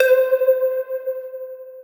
Kygo Real Attack Lead 3 Nexus Pluck
bass beach club dance edm electro electro-house electronic sound effect free sound royalty free Music